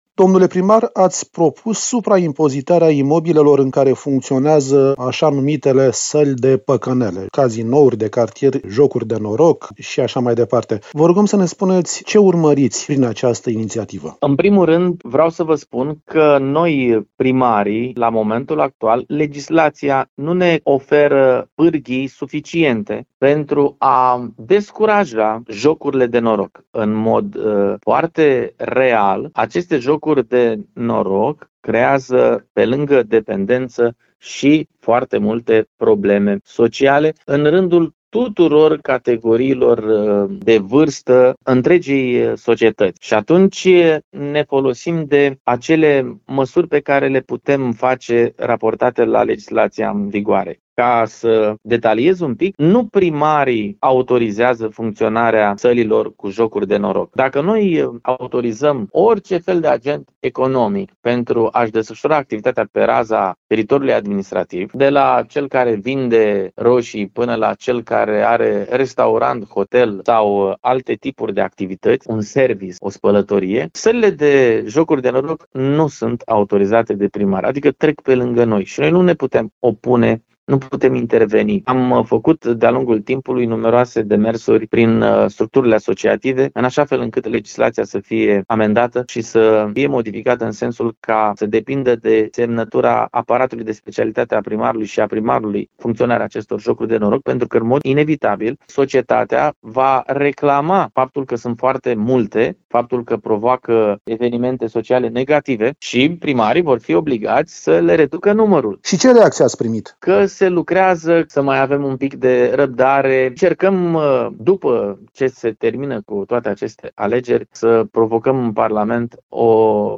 a discutat subiectul cu primarul municipiului Tulcea, Ștefan Ilie.